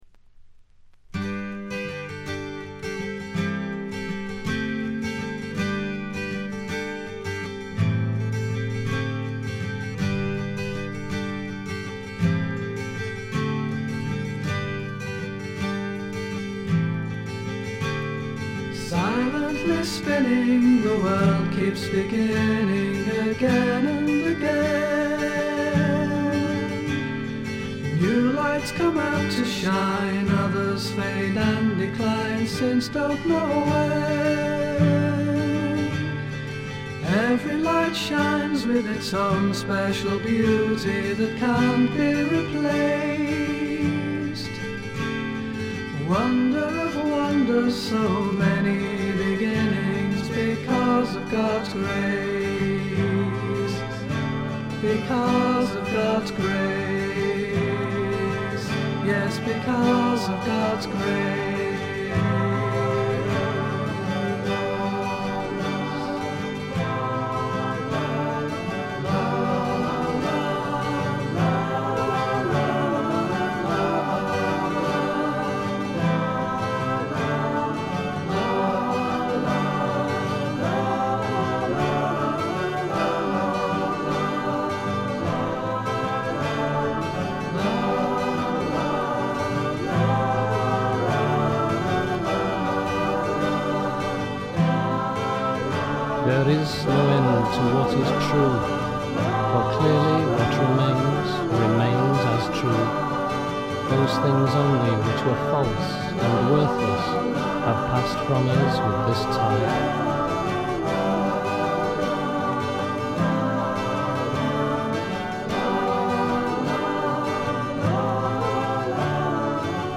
見た目に反してプレスがいまいちのようで、ところどころでチリプチ。プツ音少々。
リヴァプールの男性4人組フォークバンドによるメジャー級の素晴らしい完成度を誇る傑作です。
格調高いフォークロックの名盤。
試聴曲は現品からの取り込み音源です。
Recorded At - Canon Sound Studio, Chester